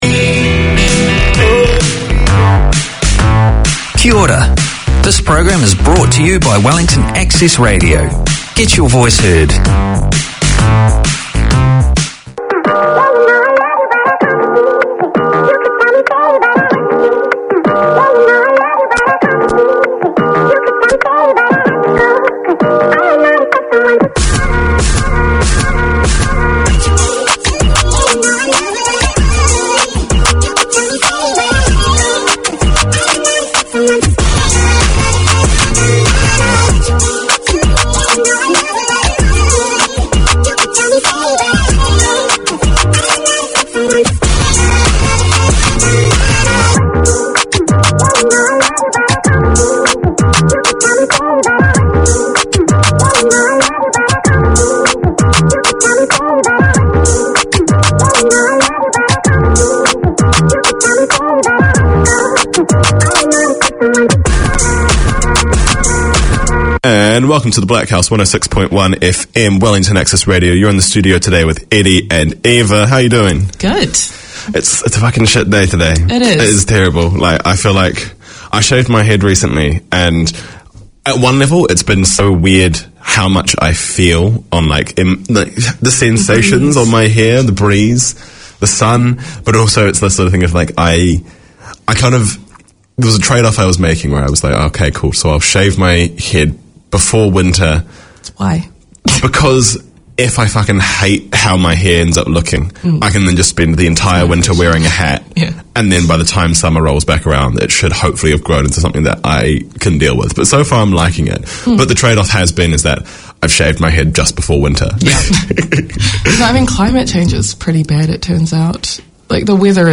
In this one-off special, Planet FM presents a snapshot of Te Matatini 2023, with vox-pops and interviews undertaken in the marketplace at Ana Wai / Eden Park where the festival took place. A celebration of the best of Kapa Haka across Aotearoa New Zealand, this year's Te Matatini festival was hosted by Ngāti Whātua Ōrākei.